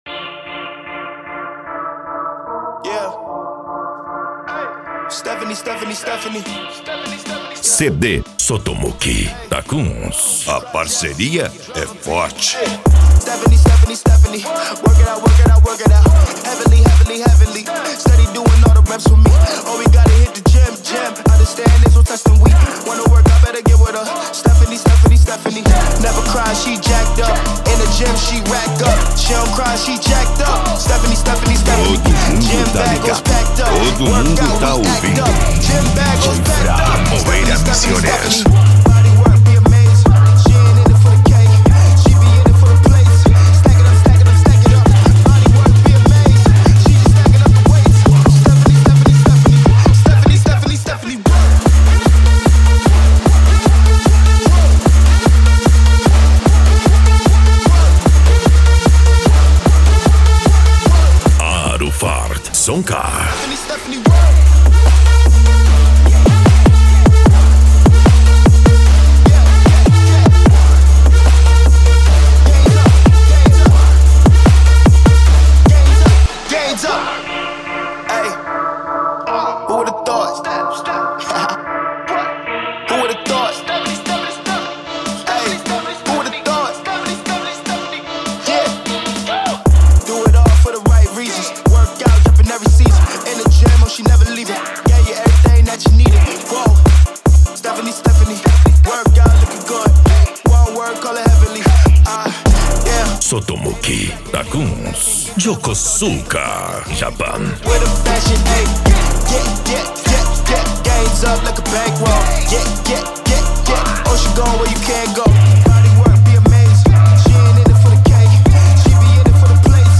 japan music